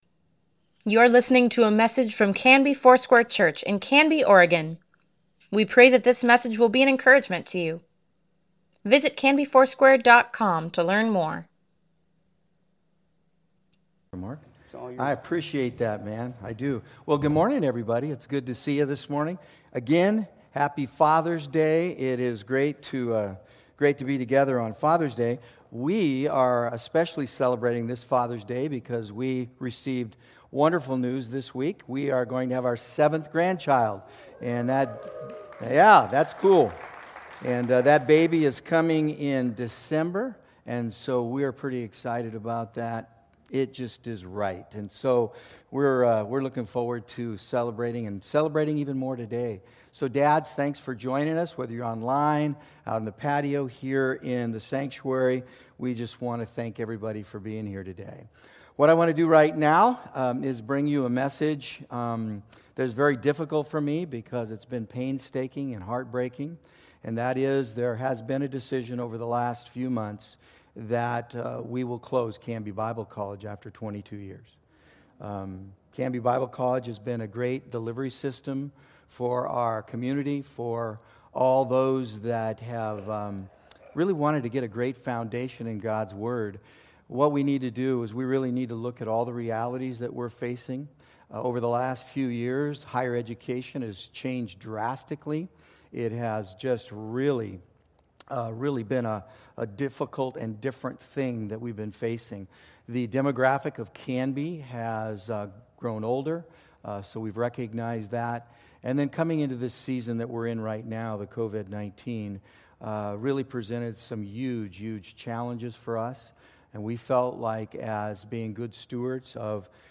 Weekly Email Water Baptism Prayer Events Sermons Give Care for Carus Steadfast - Pt. 3 June 21, 2020